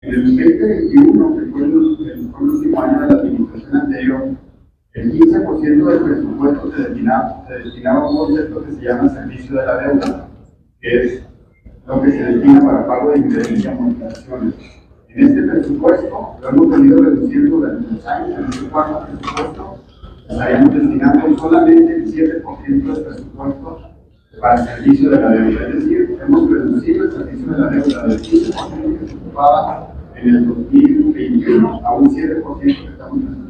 AUDIO: JOSÉ DE JESÚS GRANILLO, SECRETARIO DE HACIENDA ESTATAL 2